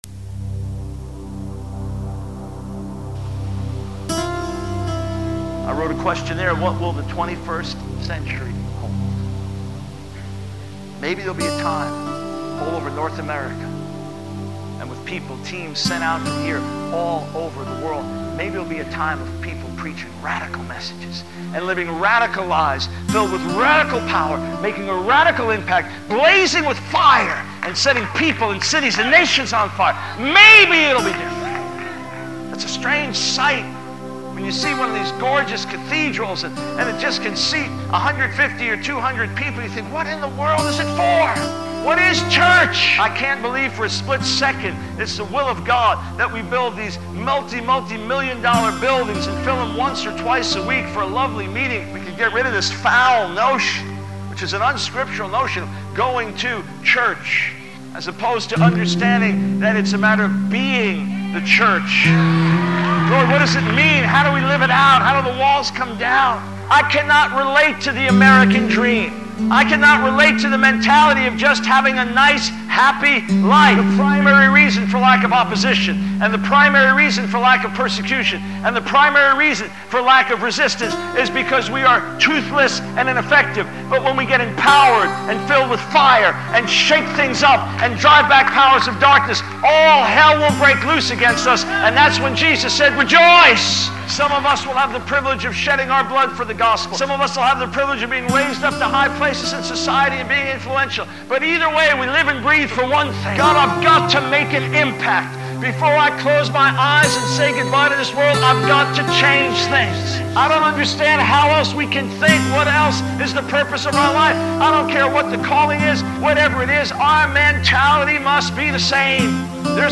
In this sermon, the preacher emphasizes the urgency and purpose of the Christian mission.
Sermon Outline